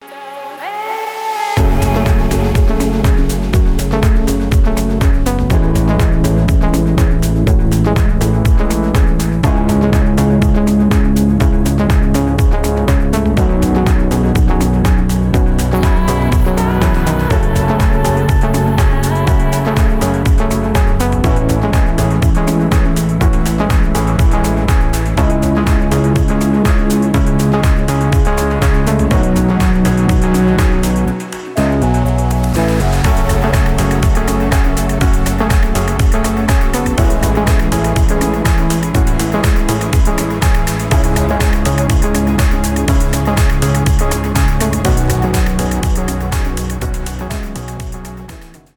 progressive house
транс